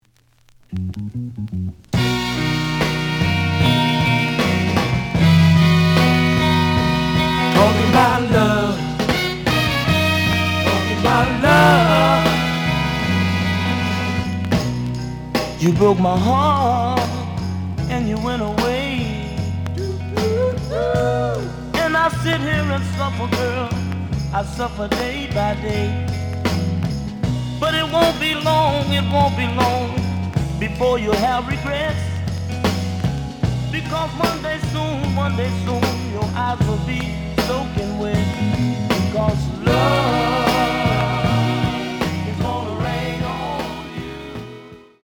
The audio sample is recorded from the actual item.
●Genre: Soul, 60's Soul
Some click noise on B side due to scratches.